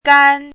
chinese-voice - 汉字语音库
gan1.mp3